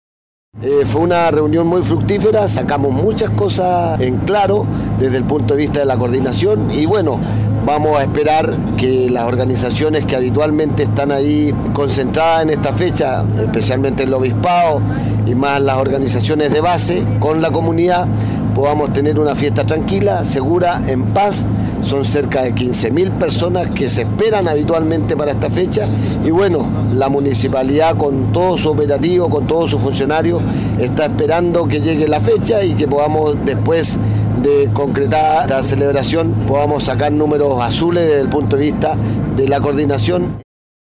El gobernador de Chiloé, Fernando Bórquez, calificó como fructífera la reunión junto a la comunidad de isla Caguach.